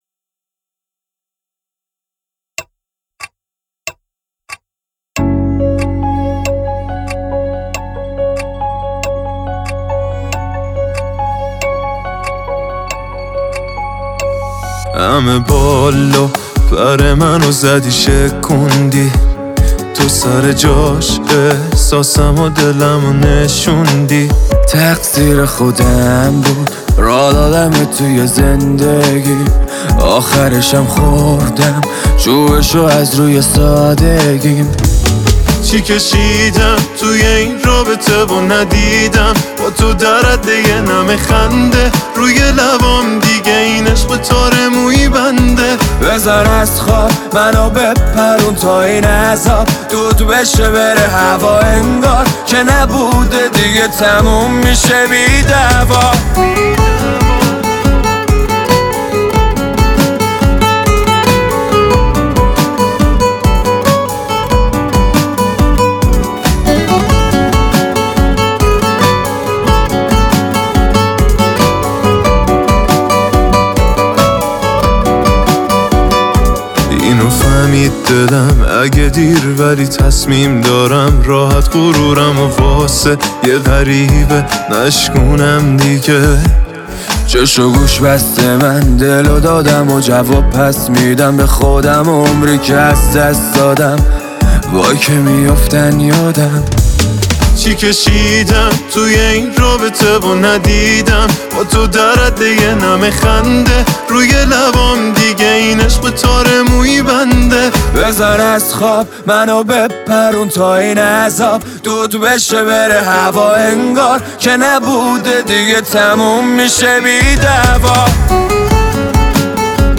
آهنگ شاد ، دانلود آهنگ0 نظر7 آگوست 2023